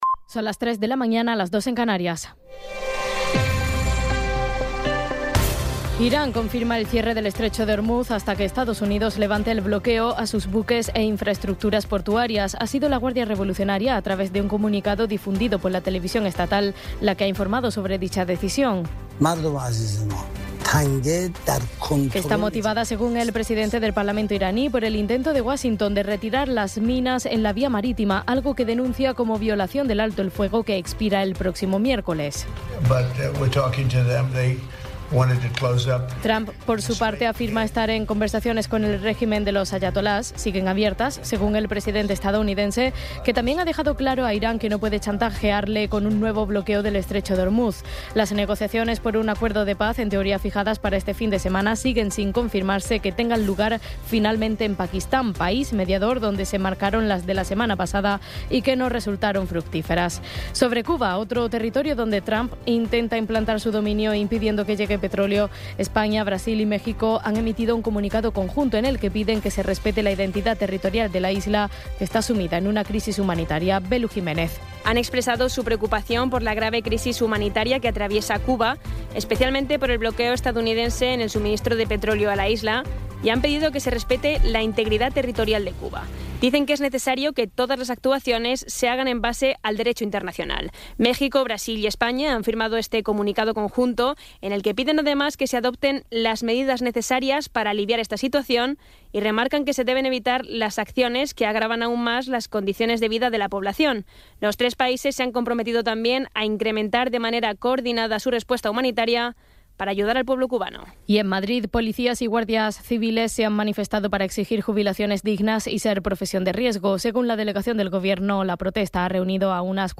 Resumen informativo con las noticias más destacadas del 19 de abril de 2026 a las tres de la mañana.